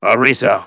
synthetic-wakewords
ovos-tts-plugin-deepponies_Spy_en.wav